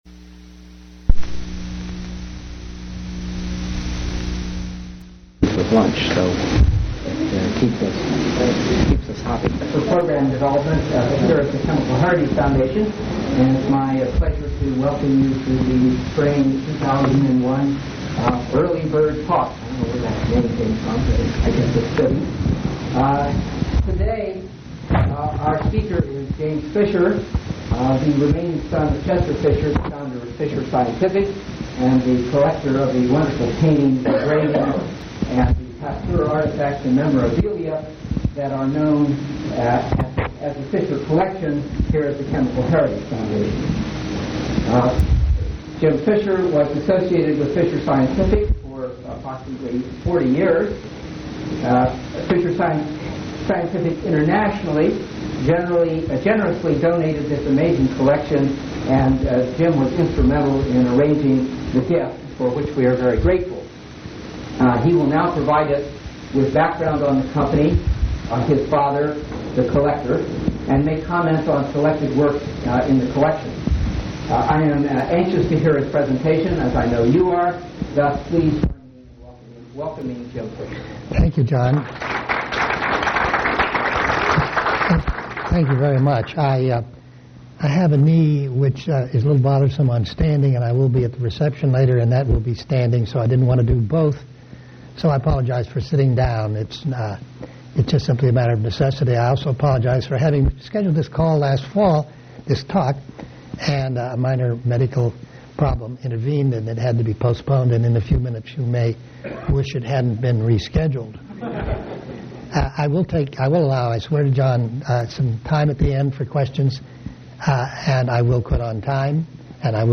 Oral histories
Place of interview Pennsylvania--Pittsburgh